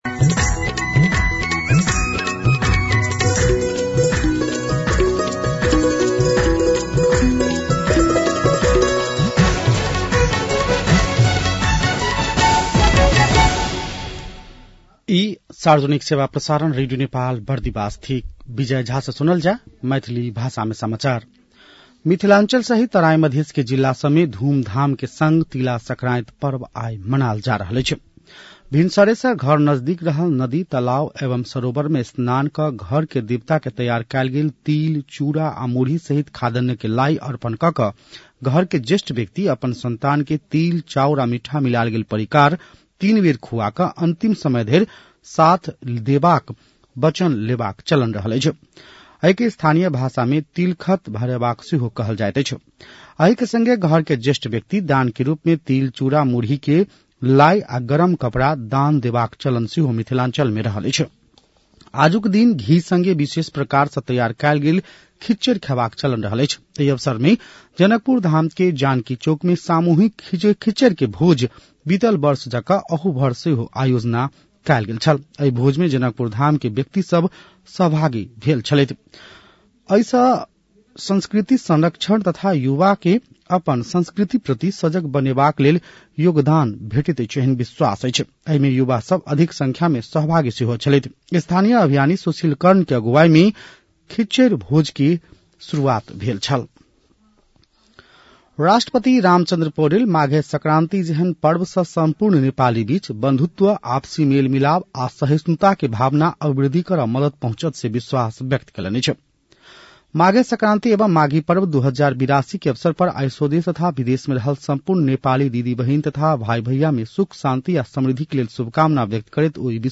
मैथिली भाषामा समाचार : १ माघ , २०८२
6.-pm-maithali-news-1-4.mp3